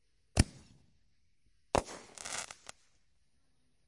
烟花 " 烟花27
描述：使用Tascam DR05板载麦克风和Tascam DR60的组合使用立体声领夹式麦克风和Sennheiser MD421录制烟花。
Tag: 高手 焰火 裂纹